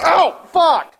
Play, download and share Fuck 1 original sound button!!!!
npc_mudcrab_death_01.mp3